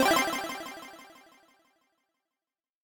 Game Start